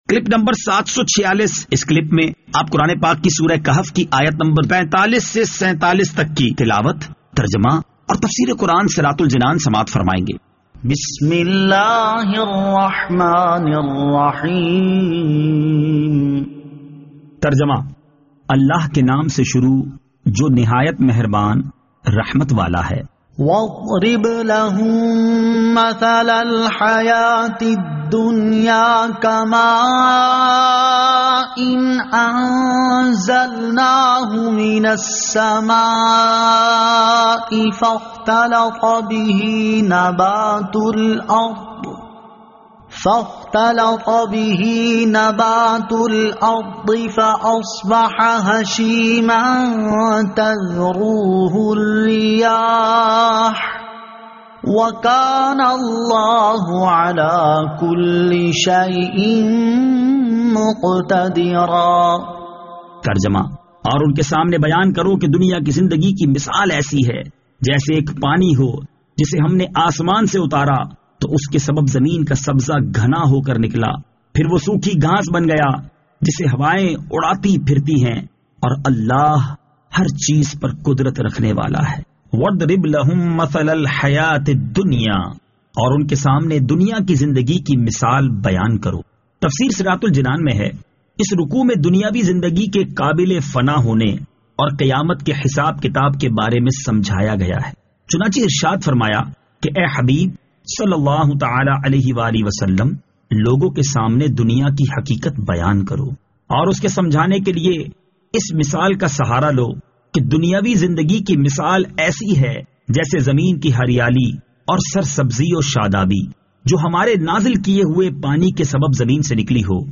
Surah Al-Kahf Ayat 45 To 47 Tilawat , Tarjama , Tafseer